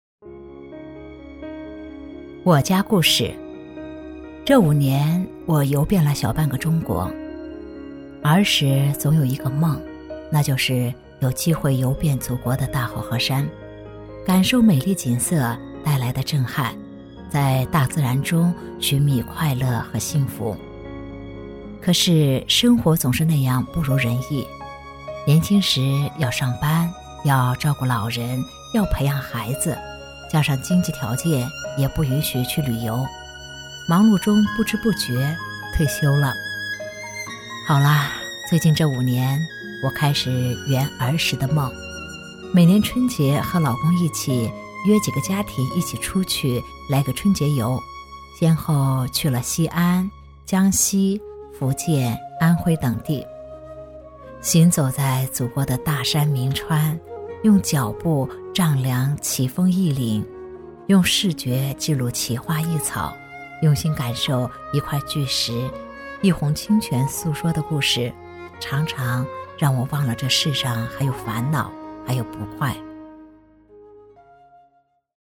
女12老年音
女12 - 我家故事（成熟感情）